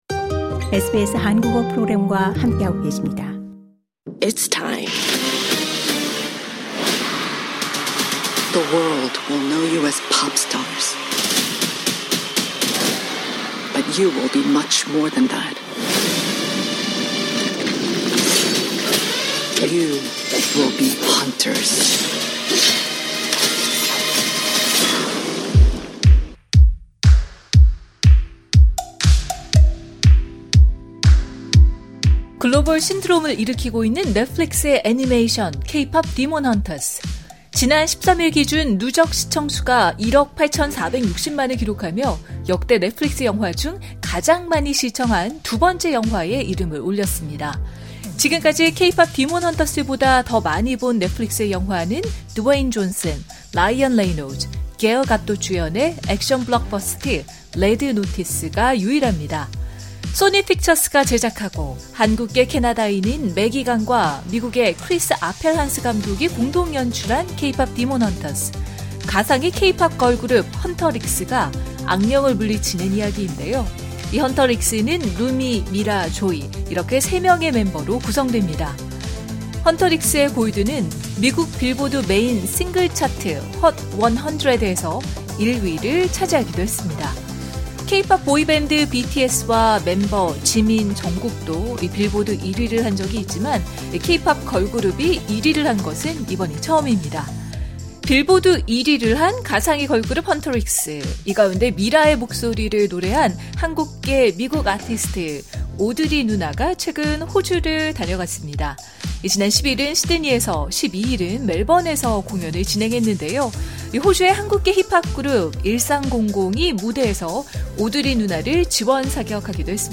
인터뷰: 호주에 온 케이팝 데몬 헌터스 ‘미라’ 목소리 '오드리 누나'